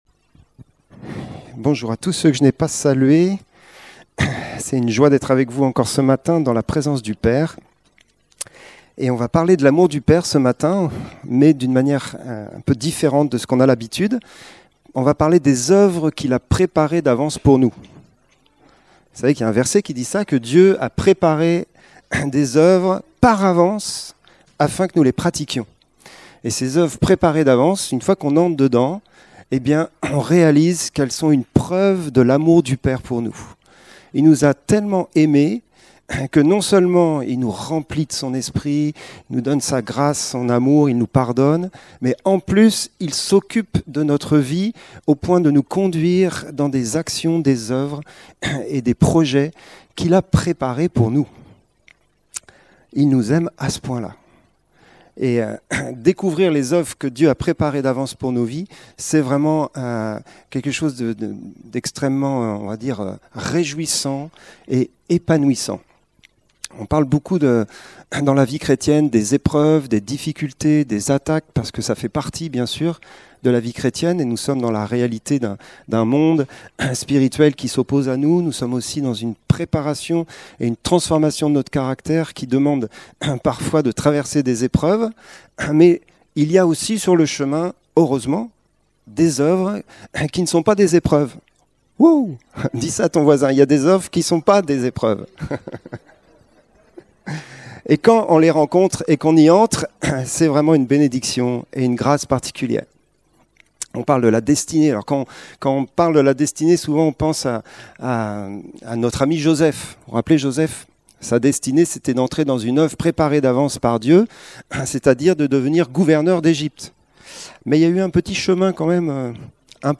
Prédications | L'ACT est une église à Toulouse